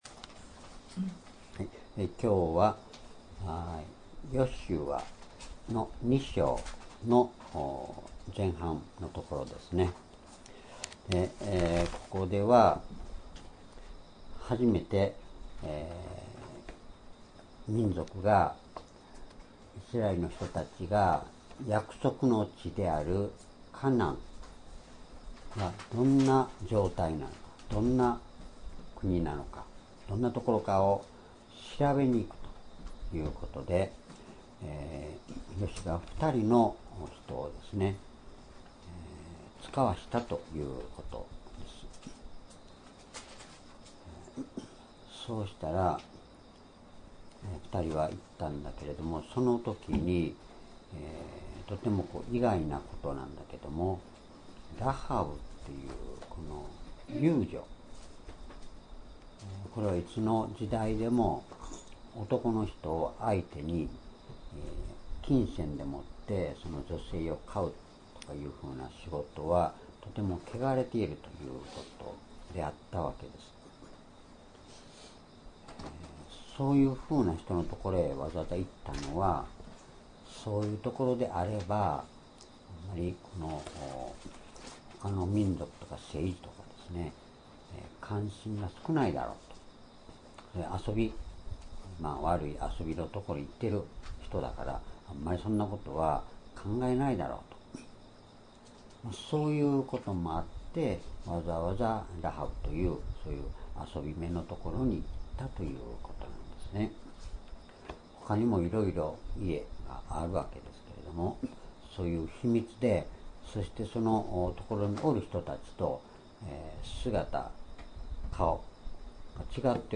主日礼拝日時 2018年9月18日 移動夕拝 聖書講話箇所 「ラハブの信仰と勇気」 ヨシュア記2章1節～14節 ※視聴できない場合は をクリックしてください。